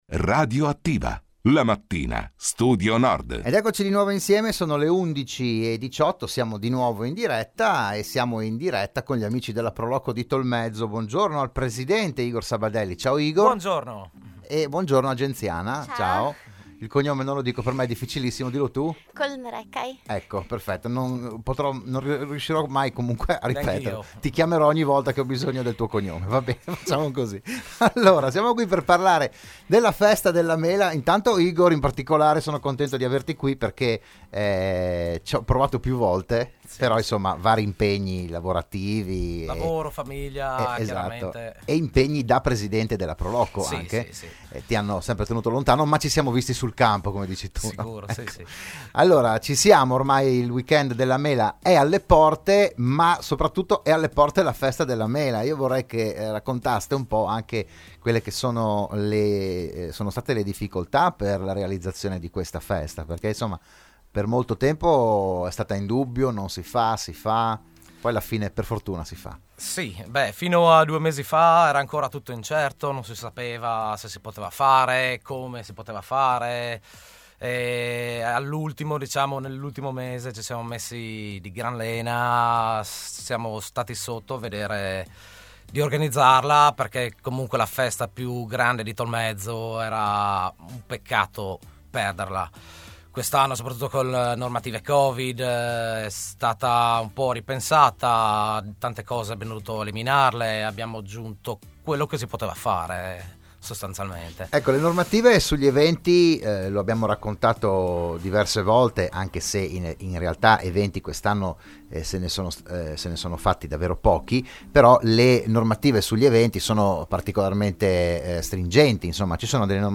L'AUDIO e il VIDEO dell'intervento a "RadioAttiva"